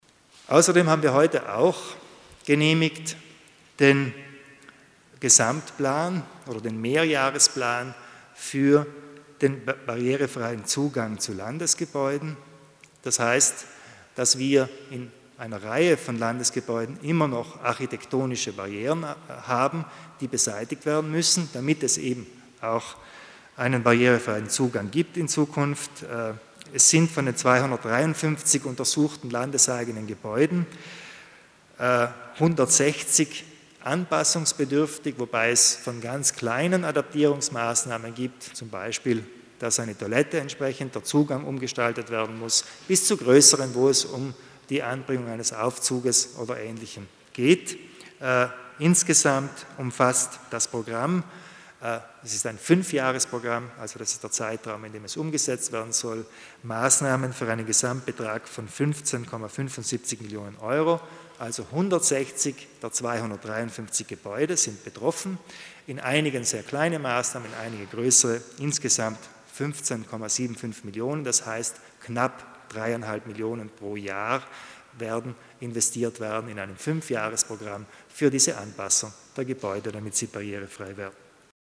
Landeshauptmann Kompatscher erklärt die Projekte für einen barrierefreier Zugang zu Landesgebäuden